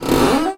carSuspension4.ogg